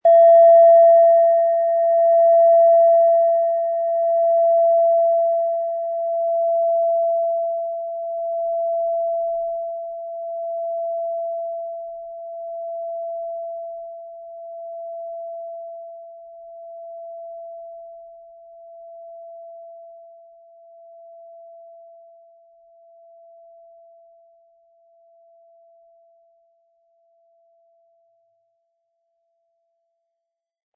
Tibetische Kopf-Herz-Fuss- und Bauch-Klangschale, Ø 12,7 cm, 320-400 Gramm, mit Klöppel
Um den Originalton der Schale anzuhören, gehen Sie bitte zu unserer Klangaufnahme unter dem Produktbild.
SchalenformBihar
MaterialBronze